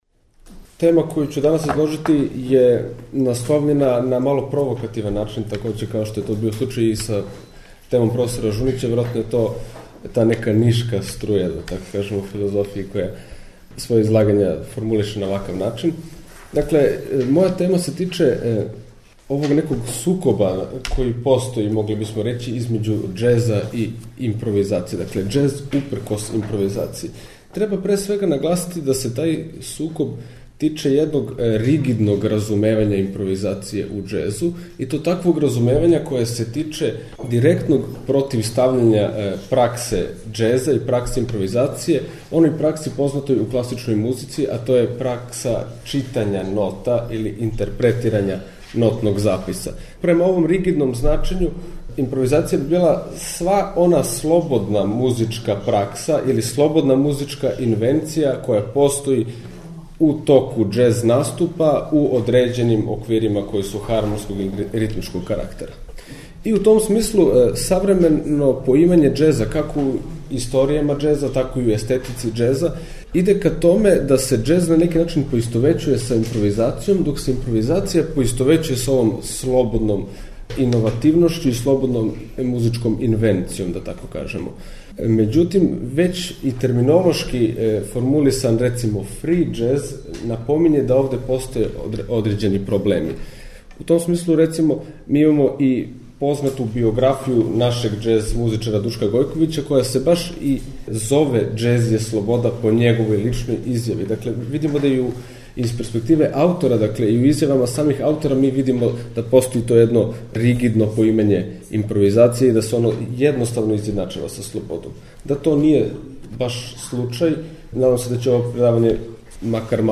Научни скупови
Следећег четвртка и петка наставићемо емитовање звучних записа са овог научног скупа, који је одржан у Заводу за проучавање културног развитка у Београду.